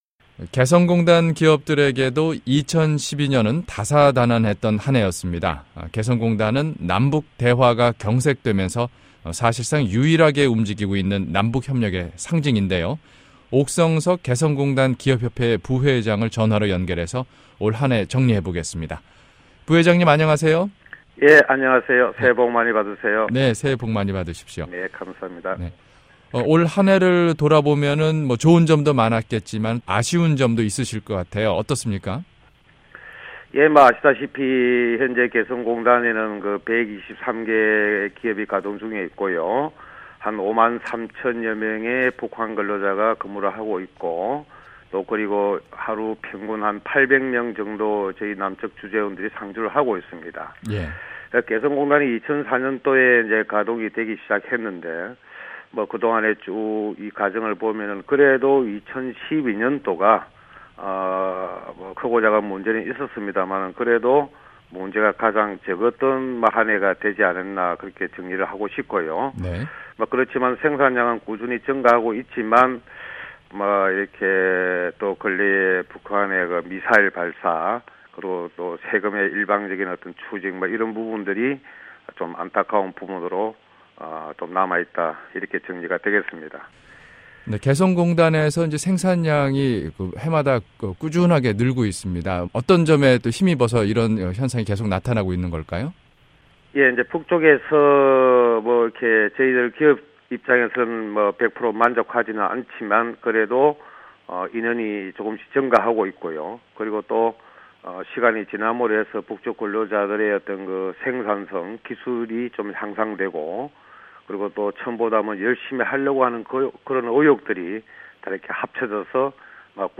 [인터뷰]